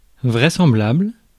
Ääntäminen
Synonyymit possible crédible probable admissible croyable plausible Ääntäminen France: IPA: /vʁɛ.sɑ̃.blabl/ Haettu sana löytyi näillä lähdekielillä: ranska Käännös Ääninäyte Adjektiivit 1. probable 2. verosímil Suku: f .